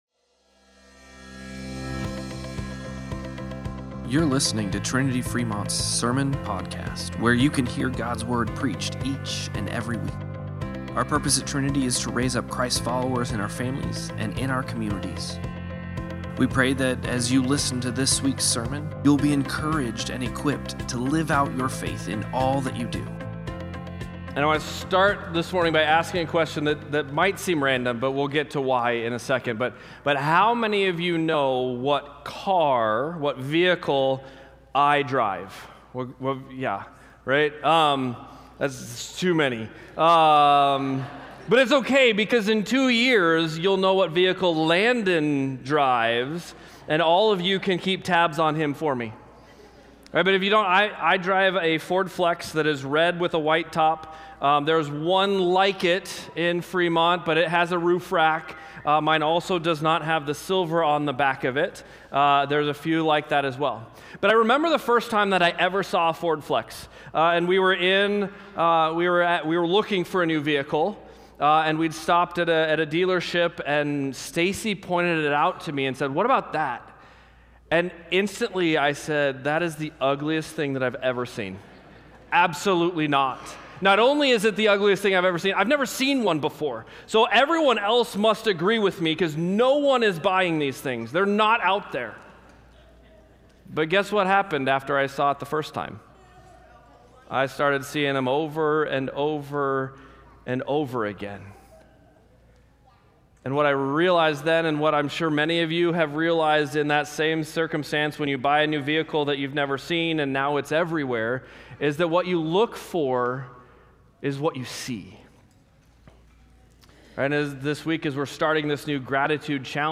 Sermon-Podcast-11-9.mp3